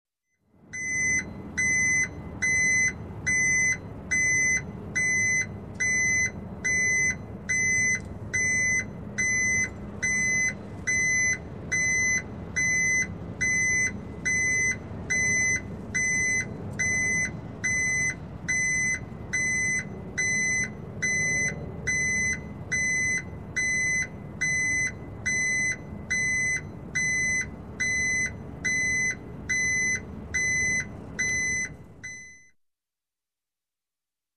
Звуки грузовиков
Грузовик сдает назад с сигналом заднего хода